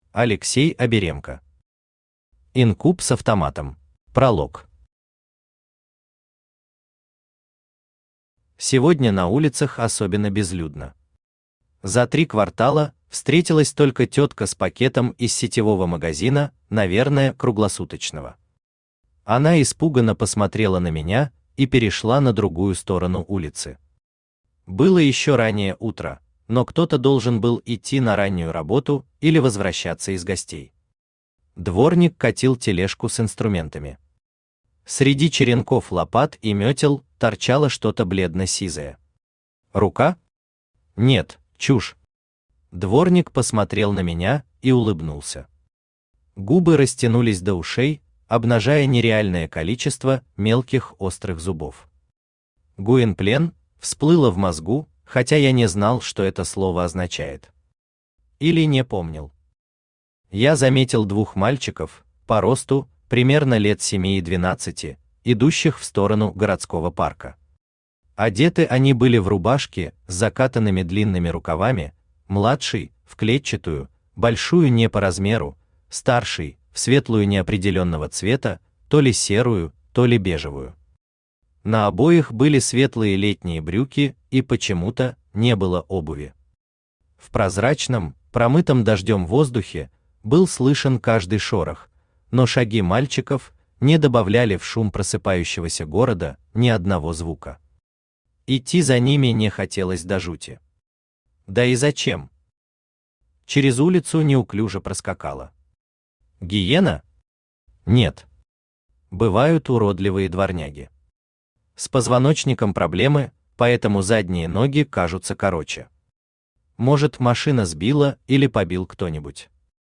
Аудиокнига Инкуб с автоматом | Библиотека аудиокниг
Aудиокнига Инкуб с автоматом Автор Алексей Евгеньевич Аберемко Читает аудиокнигу Авточтец ЛитРес.